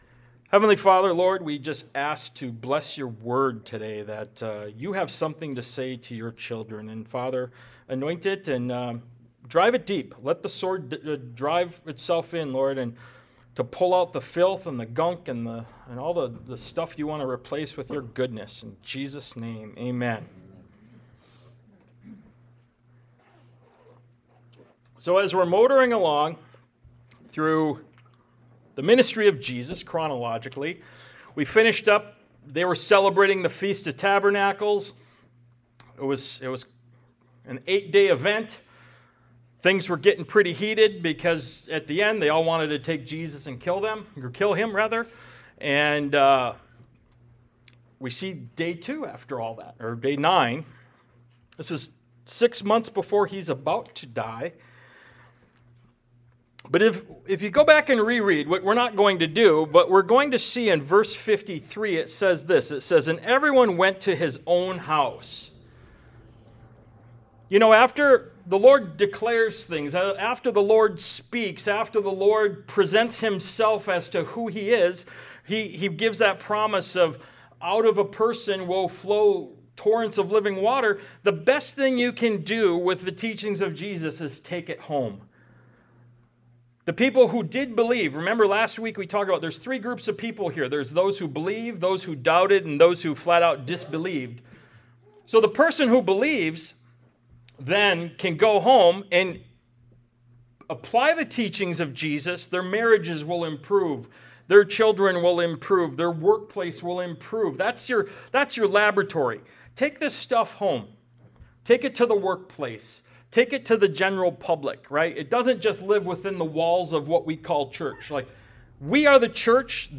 Ministry of Jesus Service Type: Sunday Morning « “What’s Your Response?”